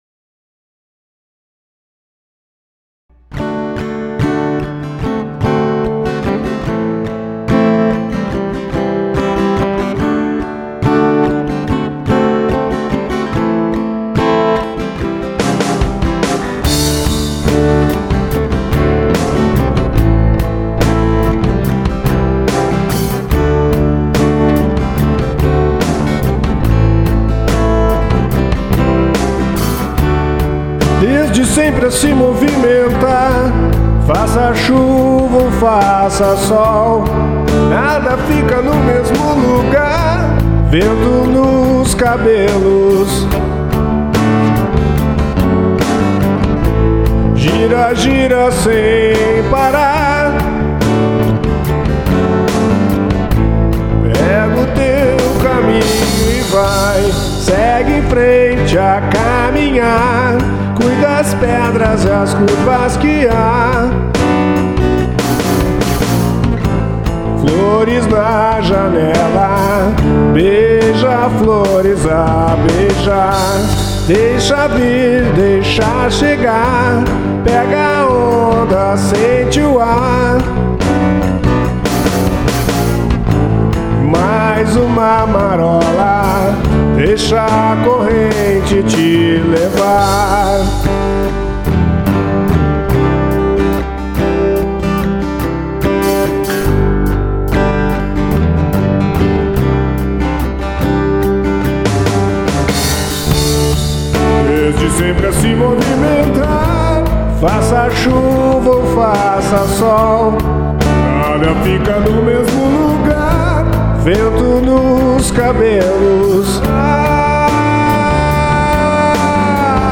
EstiloIndie